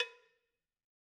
Cowbell1_Hit_v2_rr1_Sum.wav